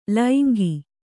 ♪ laingi